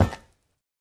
Minecraft Version Minecraft Version snapshot Latest Release | Latest Snapshot snapshot / assets / minecraft / sounds / block / vault / step7.ogg Compare With Compare With Latest Release | Latest Snapshot
step7.ogg